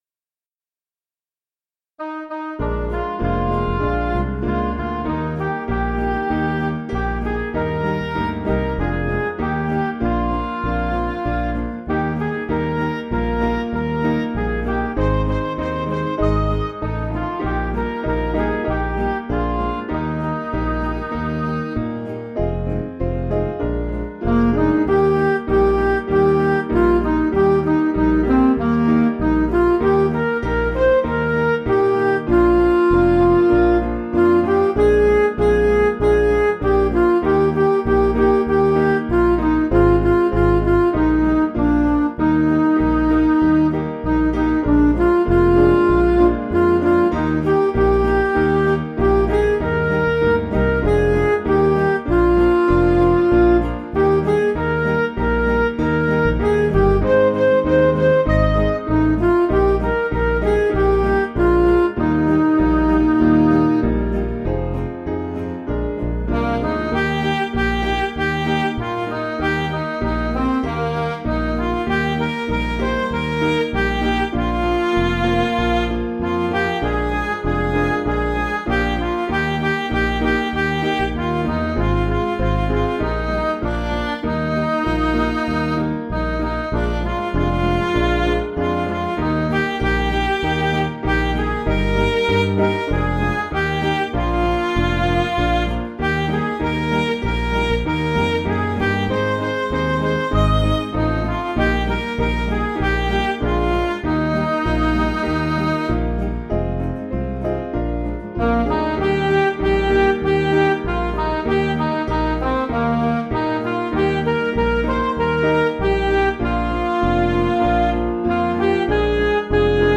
Piano & Instrumental
(CM)   4/Eb
Midi